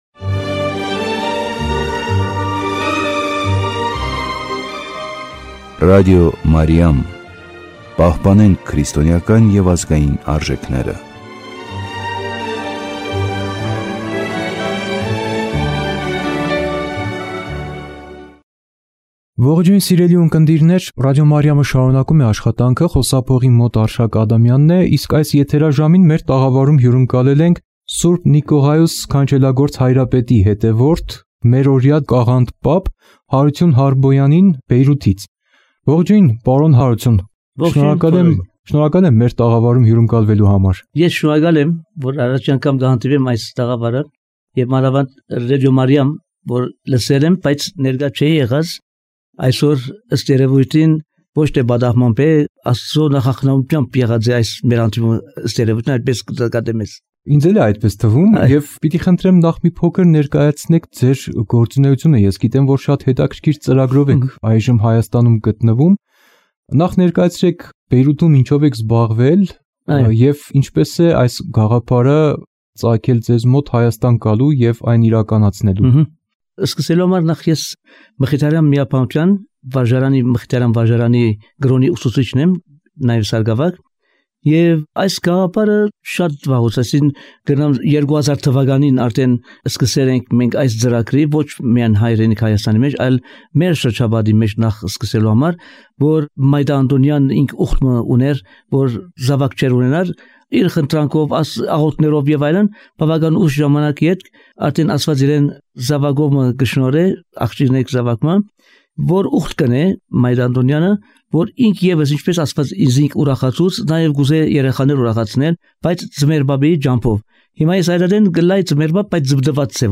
Զրույց